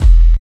108 NSE BASS.wav